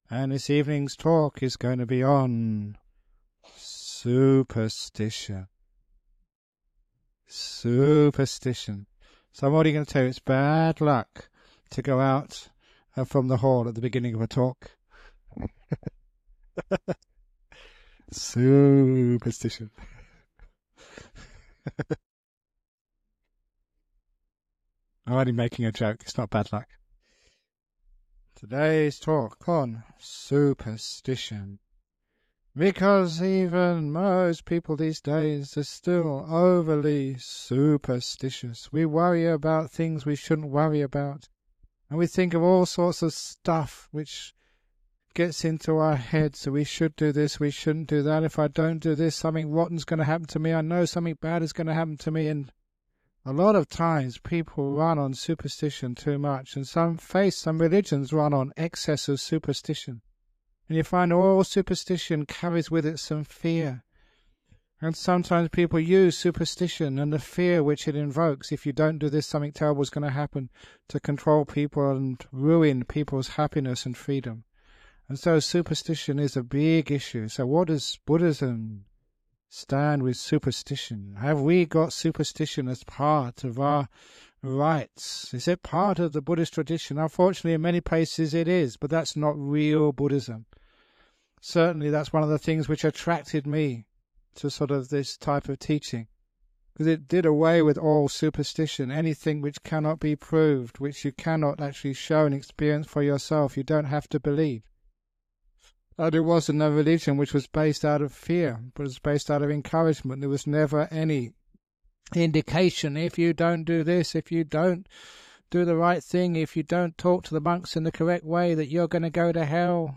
1 Deeper than reason - Beyond belief 1:03:09 Play Pause 4d ago 1:03:09 Play Pause Play later Play later Lists Like Liked 1:03:09 Ajahn Brahm gives a talk about faith and reason, and the path of truth that lays beyond both. — This dhamma talk was originally recorded using a low quality MP3 to save on file size on 27th October 2006. It has now been remastered and published by the Everyday Dhamma Network, and will be of interest to his many fans.